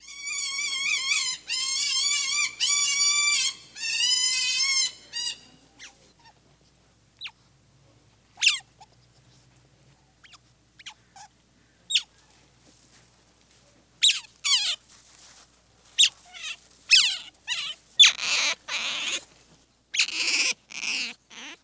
Prehistoric Birds Calls and Squeaks